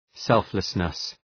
Προφορά
{‘selflısnıs}